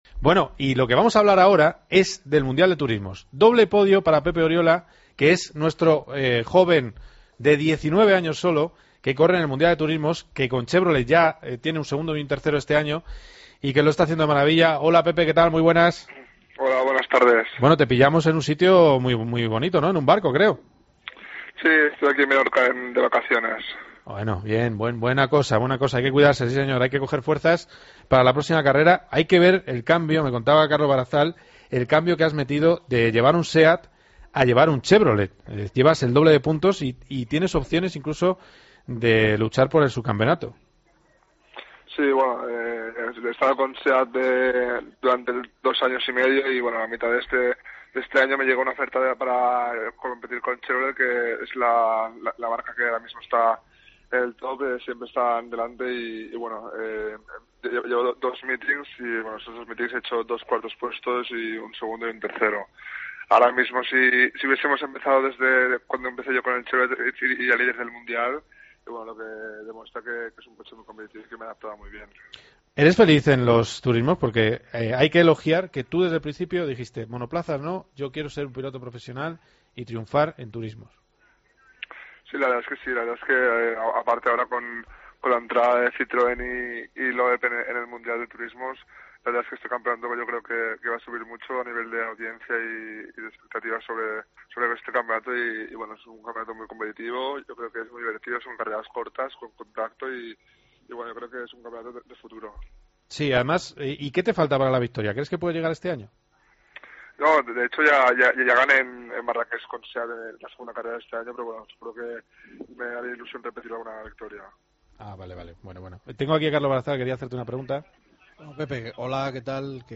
Entrevistamos al piloto español que con sólo 19 años compite en el Mundial de Turismo, donde ha cosechado en las dos últimas carreras un segundo y tercer puesto.